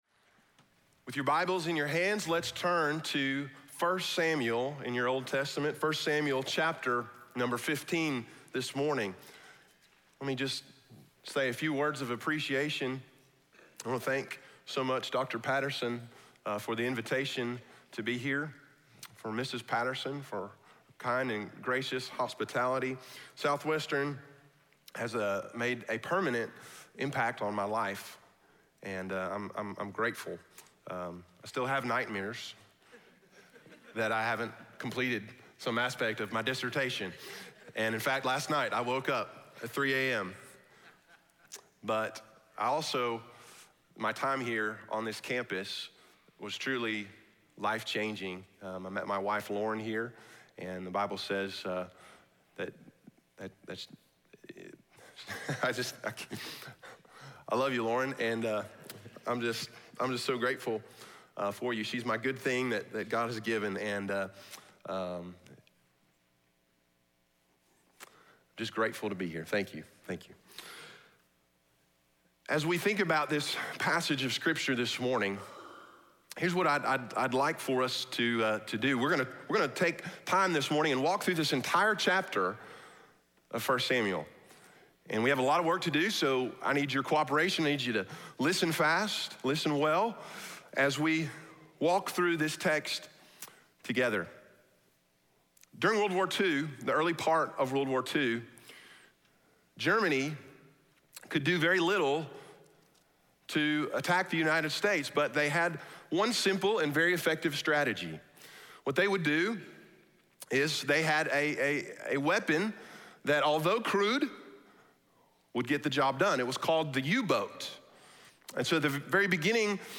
speaking on I Samuel 15 in SWBTS Chapel